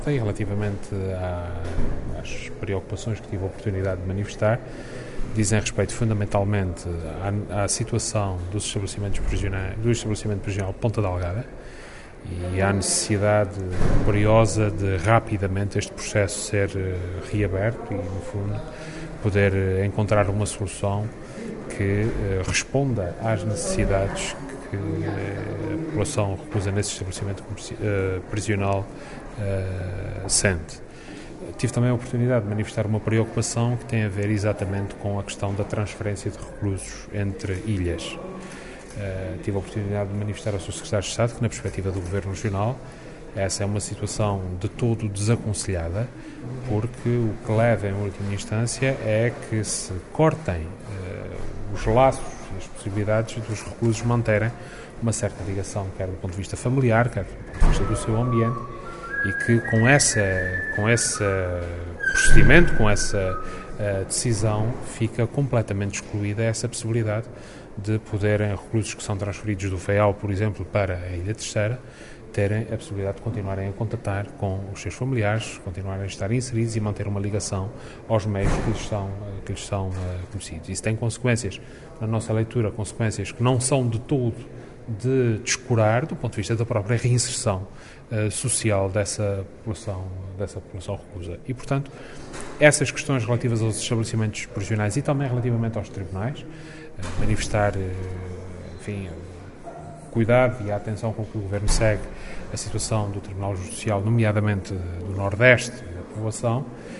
O Presidente do Governo dos Açores falava aos jornalistas após ter recebido, na cidade da Horta, o Secretário de Estado da Administração Patrimonial e Equipamentos do Ministério da Justiça, Fernando Santo.